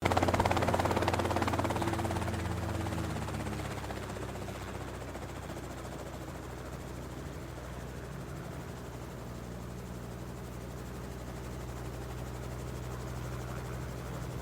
Helicopter Land and Idle
SFX
yt_EJcU7Ev8FX4_helicopter_land_and_idle.mp3